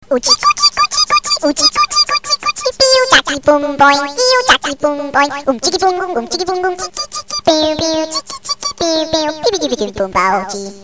веселые